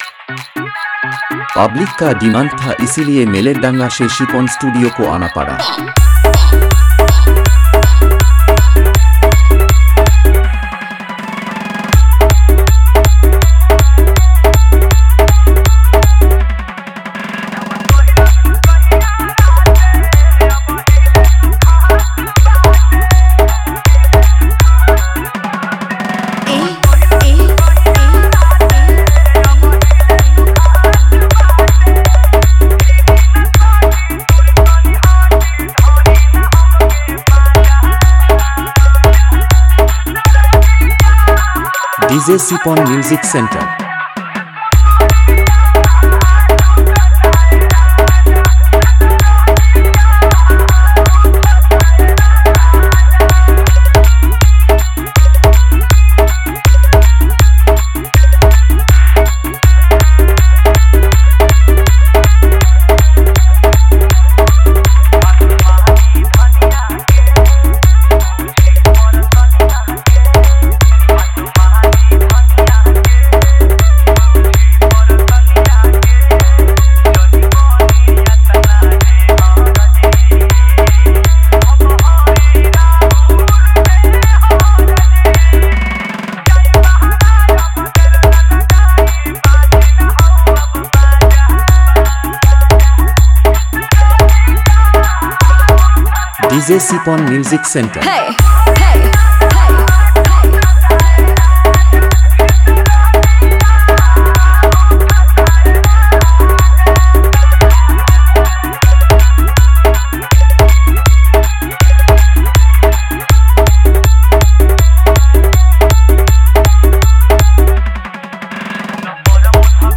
Bhojpuri Dance Humming Bass Mix song new 2025